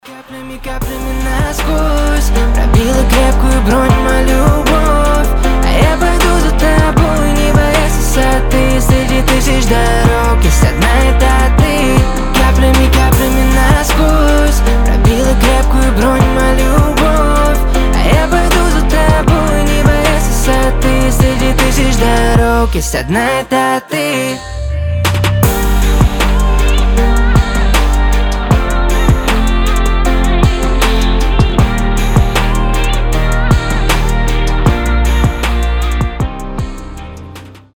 • Качество: 320, Stereo
лирика
красивый мужской голос